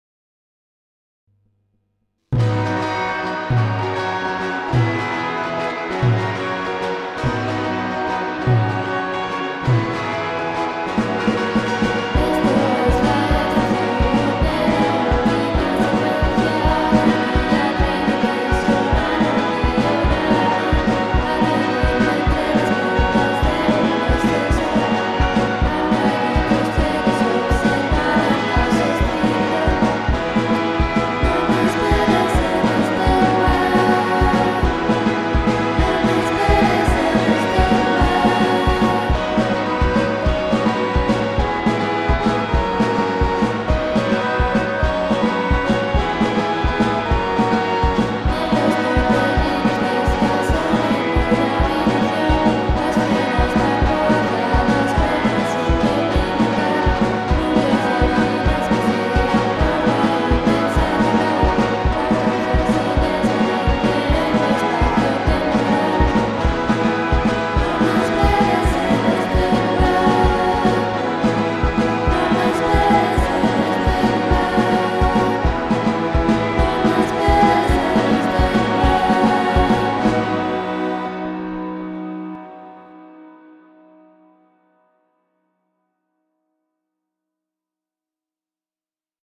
where does this beautiful lo-fi racket comes from?
A burst of perfect pop.